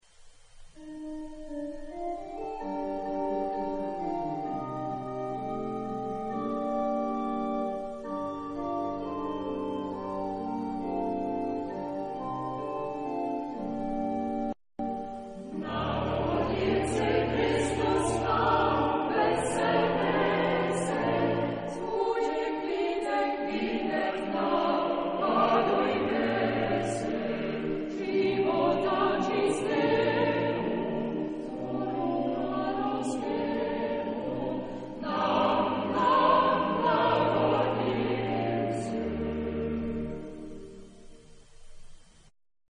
Genre-Style-Form: Christmas song
Type of Choir: SSATB  (5 mixed voices )
Instruments: Organ (1)
Tonality: F major